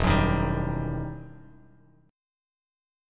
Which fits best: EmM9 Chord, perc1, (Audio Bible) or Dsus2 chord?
EmM9 Chord